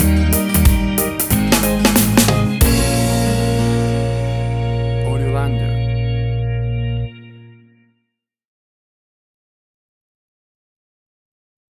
A fast and speedy piece of Reggae music, uptempo and upbeat!
WAV Sample Rate: 16-Bit stereo, 44.1 kHz
Tempo (BPM): 92